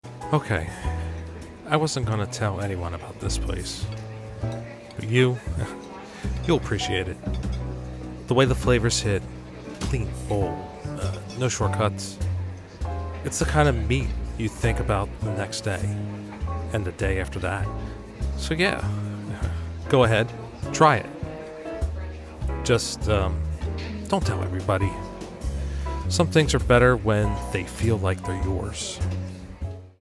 Restaurant Commercial Spot 1